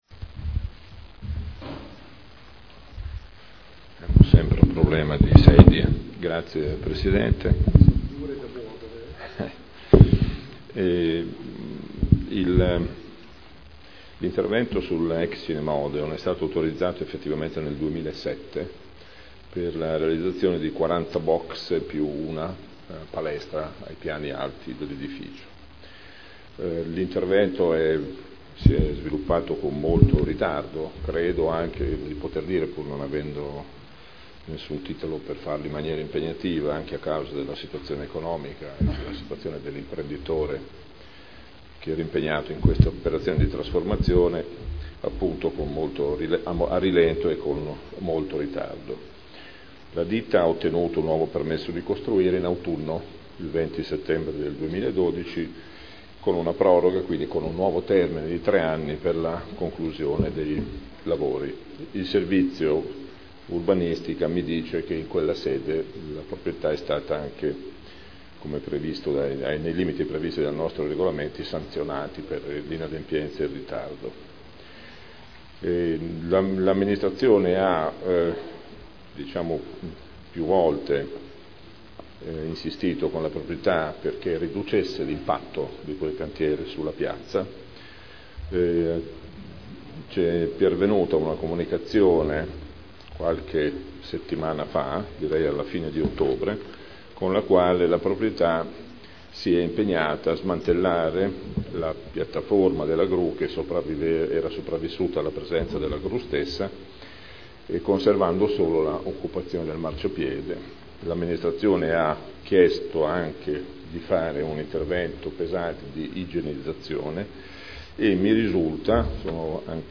Gabriele Giacobazzi — Sito Audio Consiglio Comunale